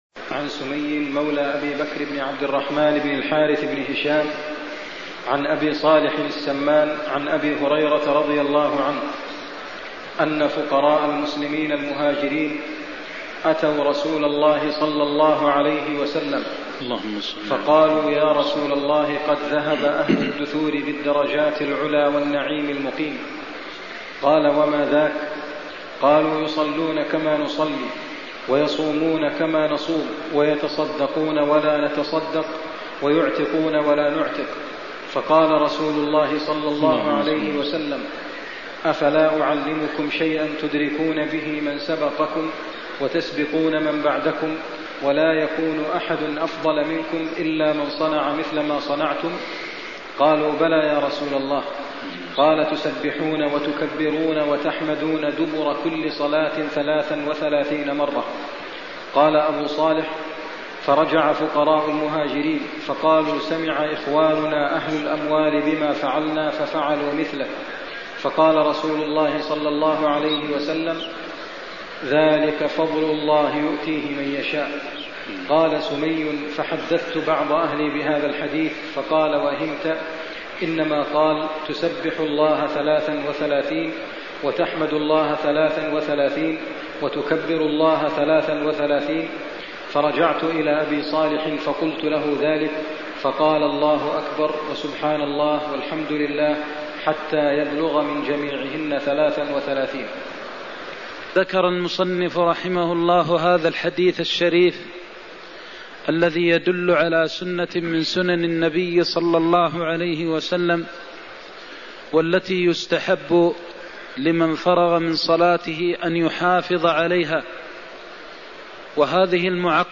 المكان: المسجد النبوي الشيخ: فضيلة الشيخ د. محمد بن محمد المختار فضيلة الشيخ د. محمد بن محمد المختار استحباب التسبيح التحميد والتكبير بعد الصلاة (124)